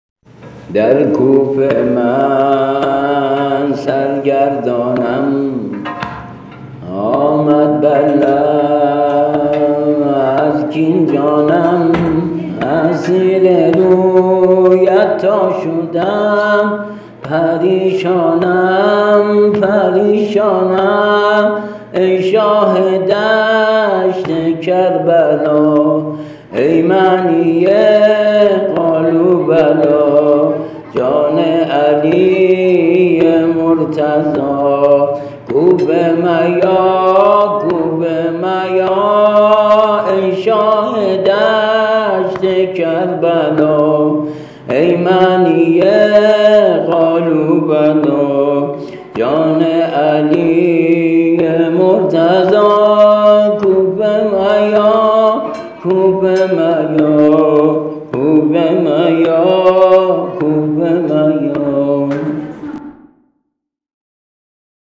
◾نوحه‌ی سینه‌زنی‌شهادت